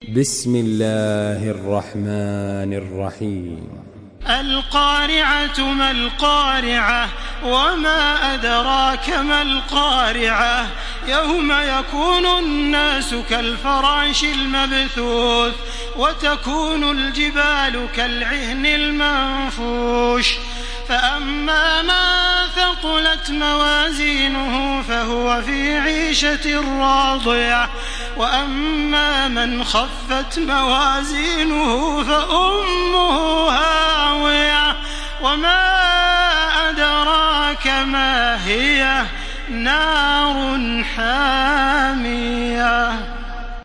Download Surah Al-Qariah by Makkah Taraweeh 1434
Murattal